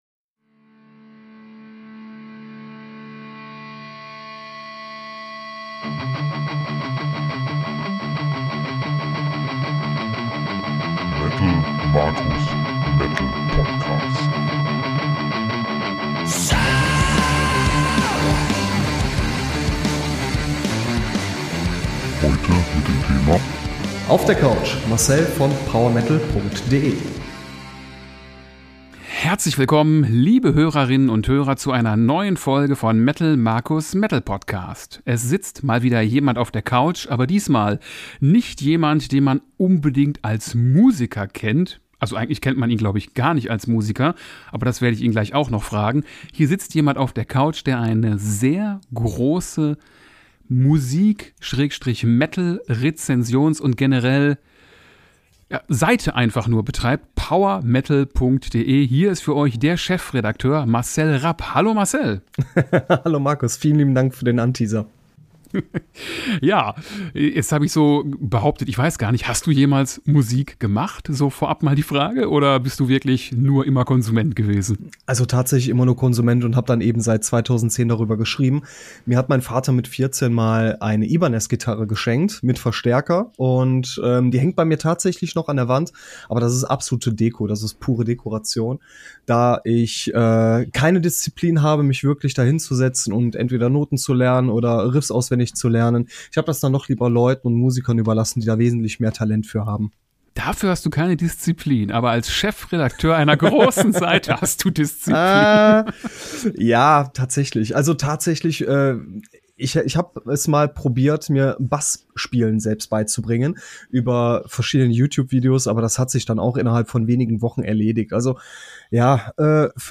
Freut euch auf eine knappe Dreiviertelstunde feinsten Nerdtalk & Fanboy-Alarm.